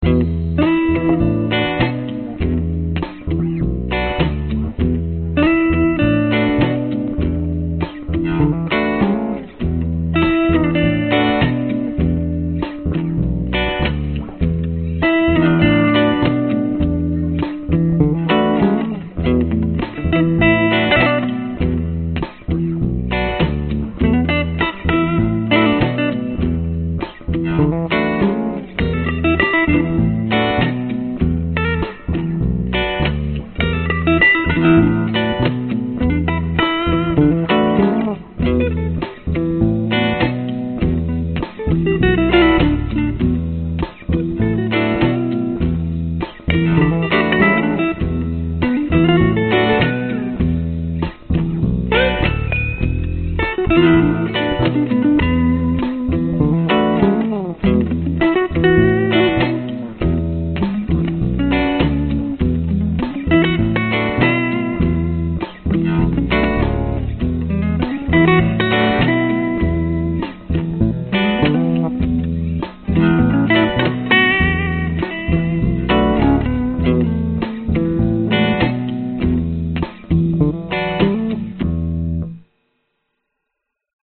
拉链中的单声道干式和声。
Tag: 贝斯 蓝调 吉他 循环播放